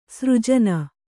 ♪ sřjana